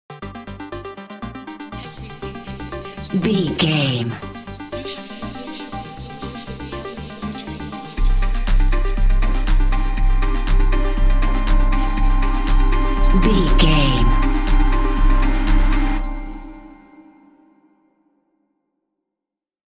Epic / Action
Fast paced
Aeolian/Minor
Fast
dark
futuristic
groovy
aggressive
synthesiser
drum machine
vocal
house
electro dance
techno
trance
synth bass
upbeat